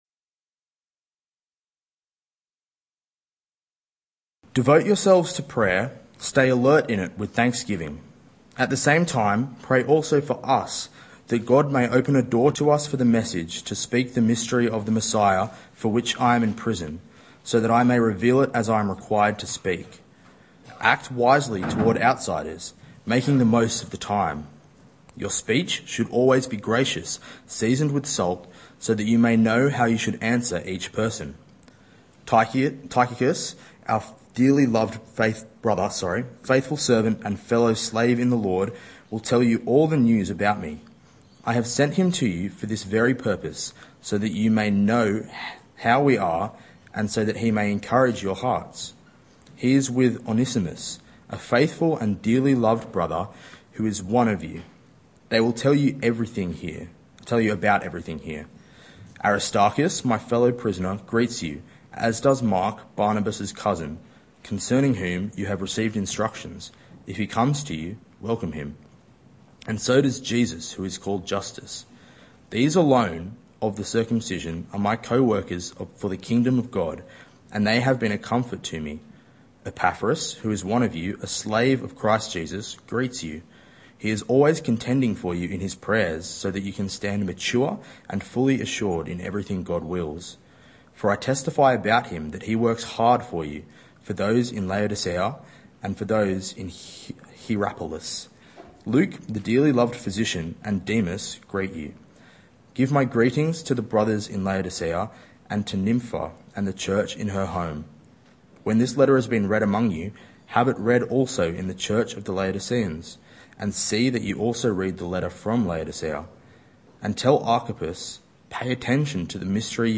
Youth Church